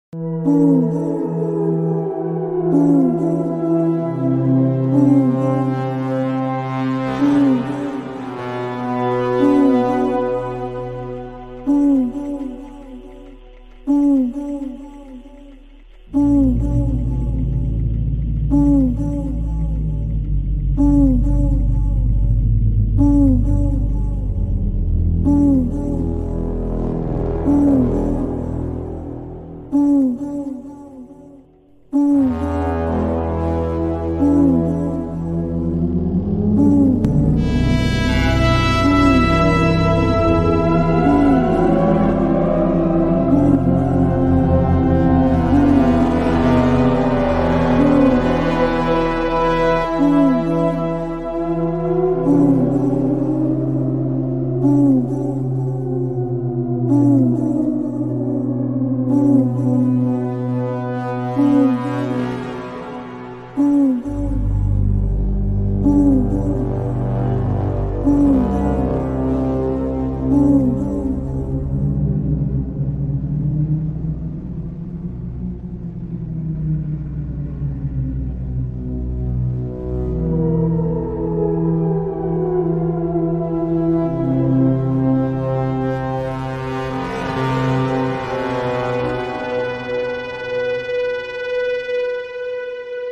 Scary Halloween Night